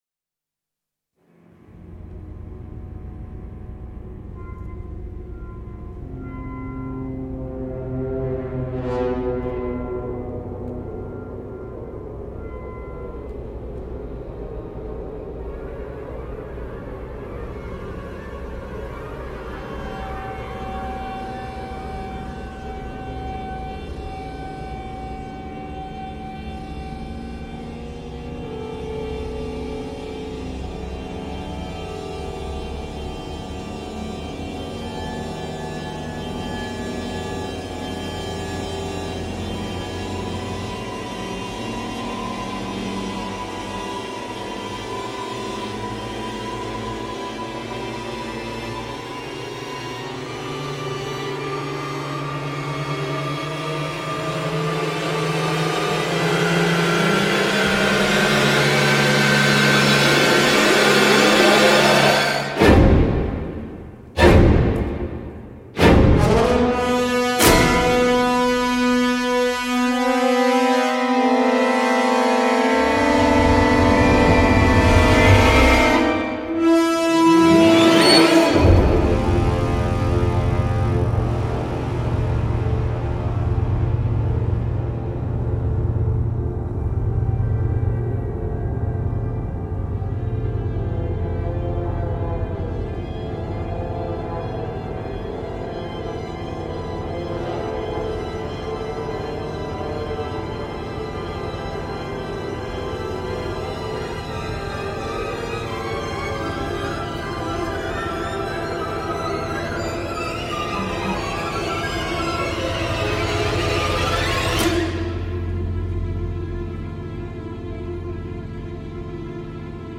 旗舰木管乐器
在Teldex得分舞台上录制，声音自然，圆润，并与其他柏林系列完美融合。
所有乐器都以相同的自然增益水平精心录制，音乐家位于传统管弦乐队的座位位置。